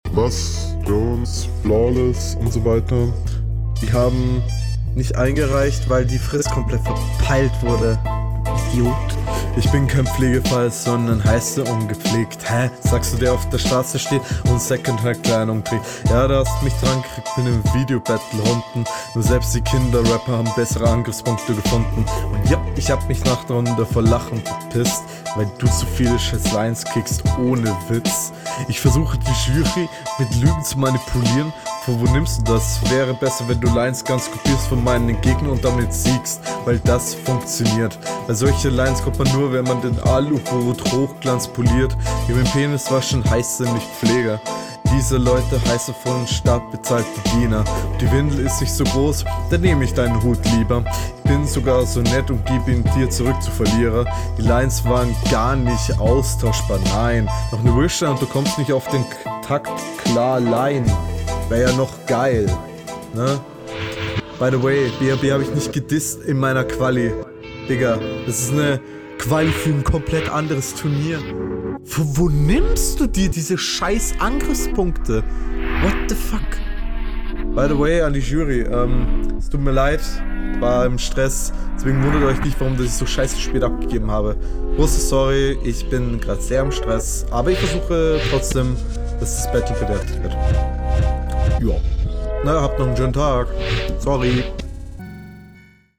Hall immer noch viel zu viel.